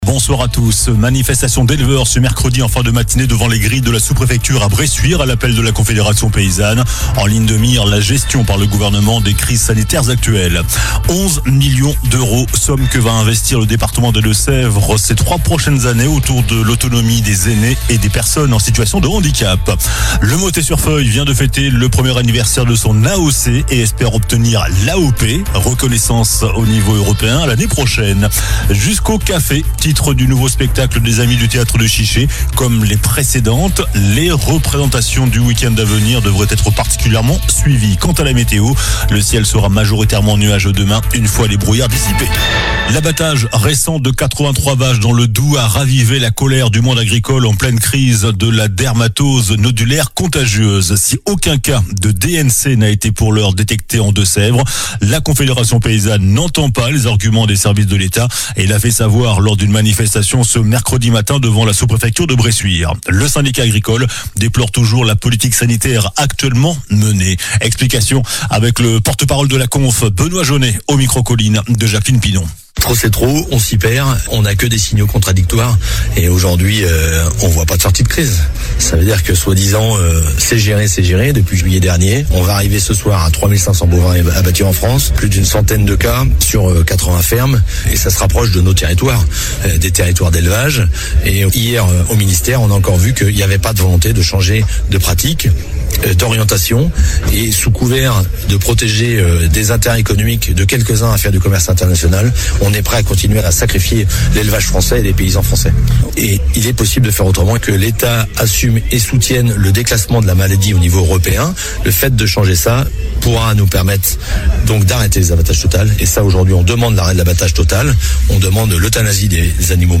Journal du mercredi 10 décembre (soir)